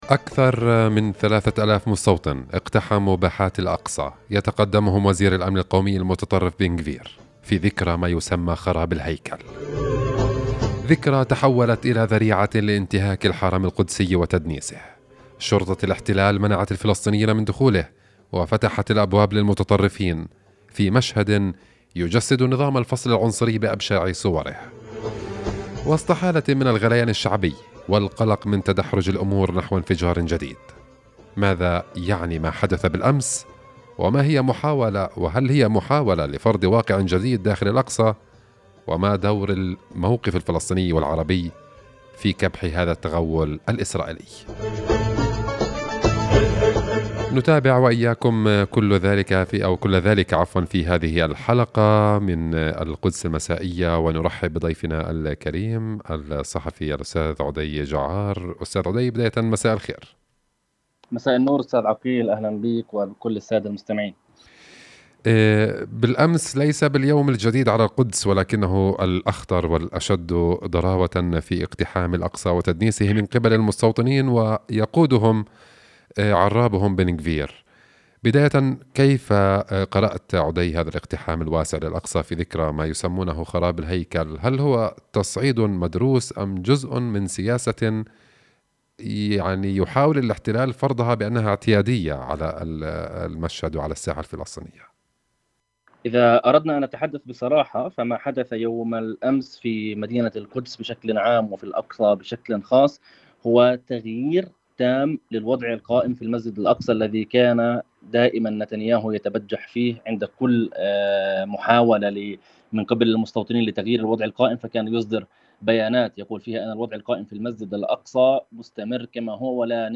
خاص – اذاعة القدس